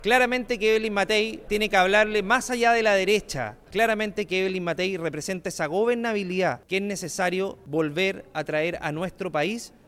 En tanto, el diputado de Renovación Nacional, Andrés Longton, señaló que la candidata apunta a esta diferenciación porque busca llegar al centro por un lado y, por otro, mostrar a los electores de derecha por qué ella da más garantías que la carta presidencial de Republicanos.